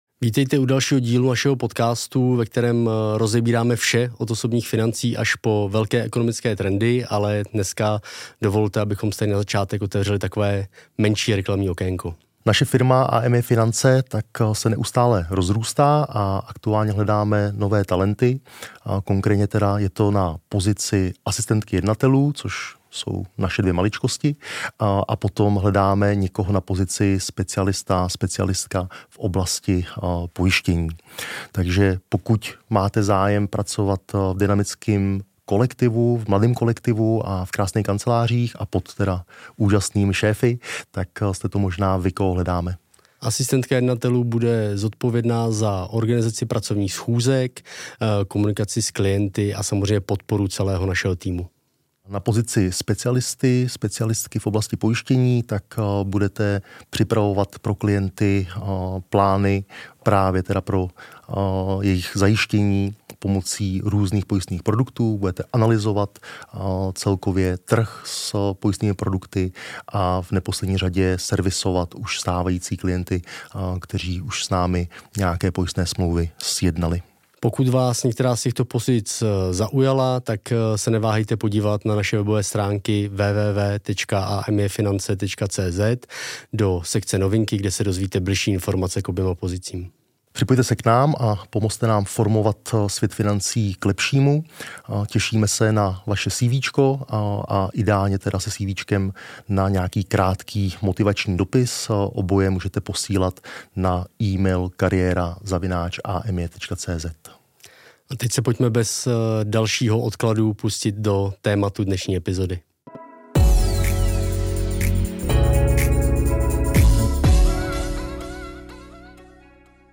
Dalibor Dědek, zakladatel a majitel společnosti Jablotron, jeden z nejbohatších Čechů, filantrop a aktivní odpůrce ruské invaze na Ukrajině, se s námi v této 1. části našeho rozhovoru podělil o svůj příběh.